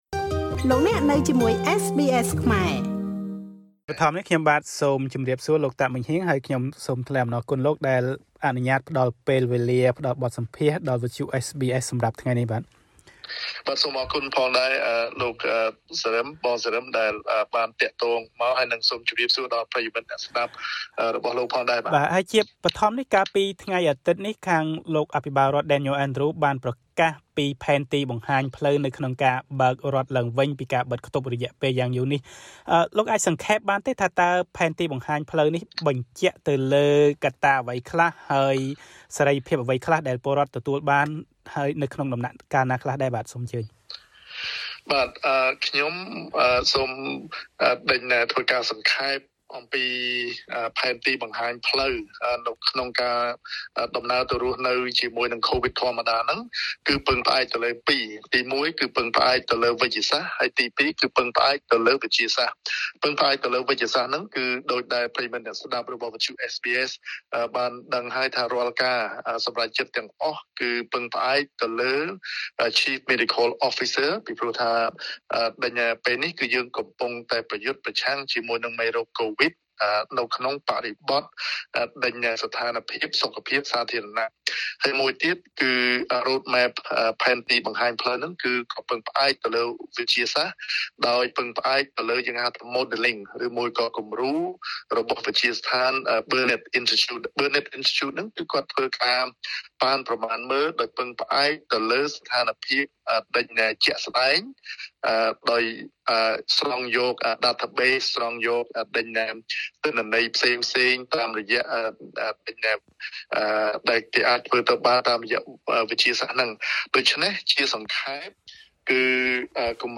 រដ្ឋវិចថូរៀបានប្រកាសពីផែនទីបង្ហាញផ្លូវ (Roadmap) របស់ខ្លួនក្នុងការចាក់ចេញពីការបិទខ្ទប់ដ៏យូរនៅក្នុងរដ្ឋនេះ ក្រោយពីអត្រាចាក់វ៉ាក់សាំង២ដូស្តសម្រេចបាន ៧០% និង ៨០%។ លោក តាក ម៉េងហ៊ាង សមាជិកសភានៃរដ្ឋវិចថូរៀពន្យល់បន្ថែម។